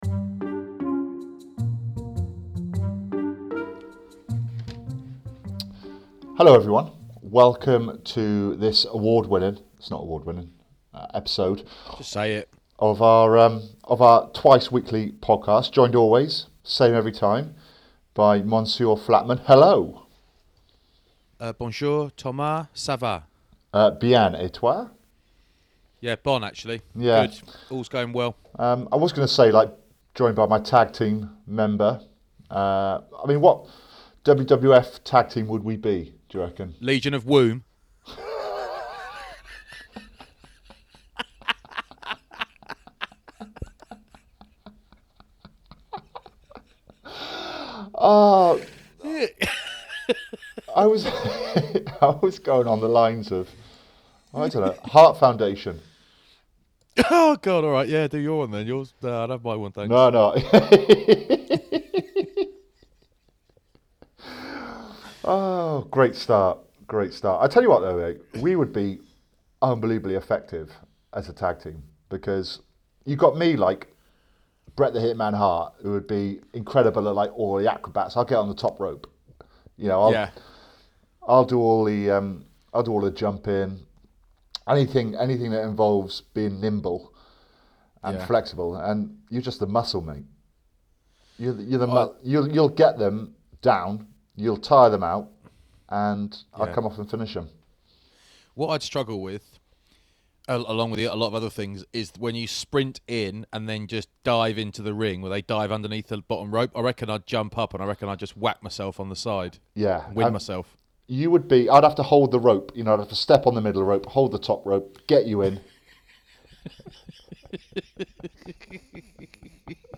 Sir Chris hoy joins us on the phone.